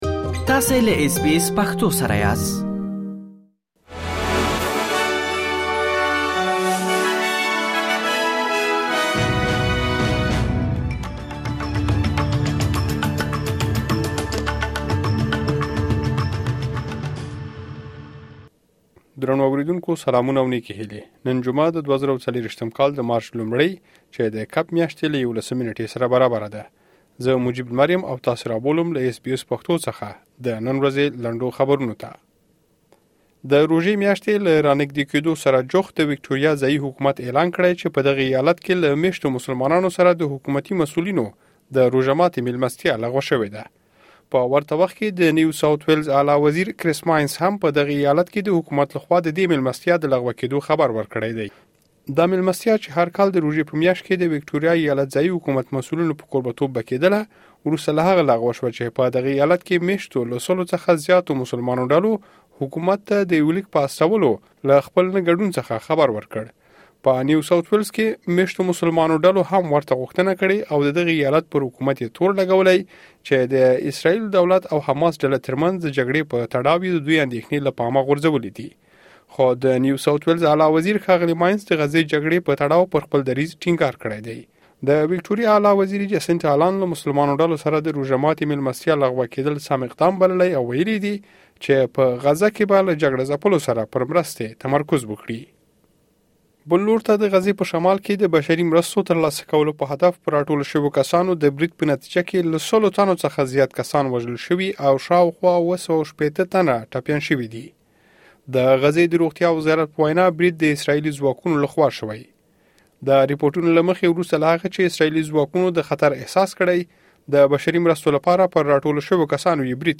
د اس بي اس پښتو راډیو د نن ورځې لنډ خبرونه| ۱ مارچ ۲۰۲۴